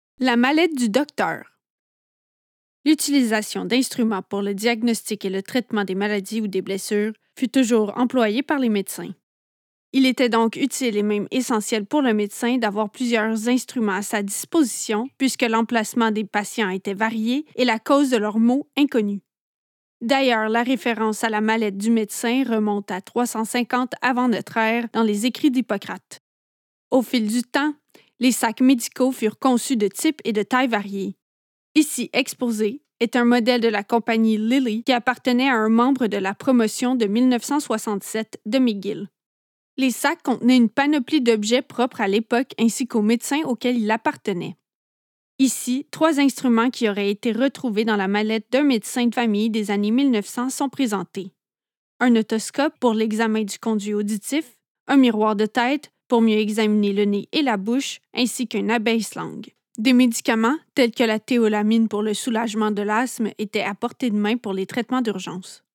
Below are audio guides for the artifacts displayed.